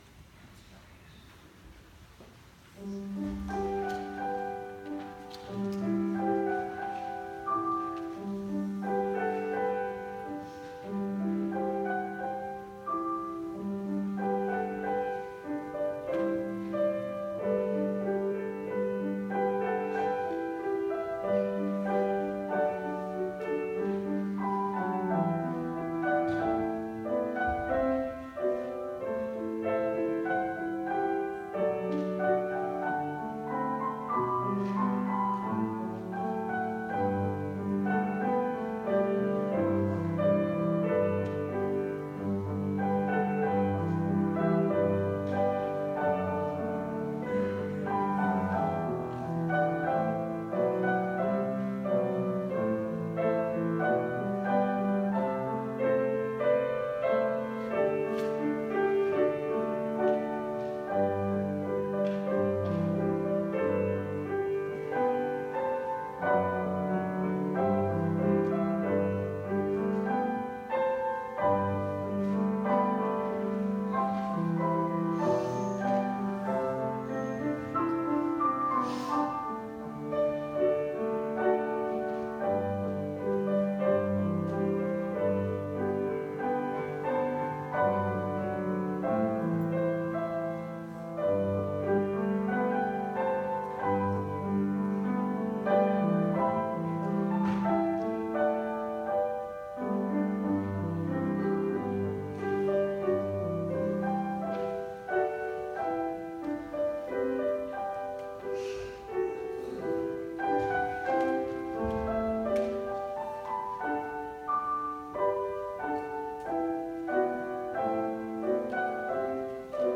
Series: Guest Speaker
Matthew 9:35-38 Service Type: Sunday Worship Today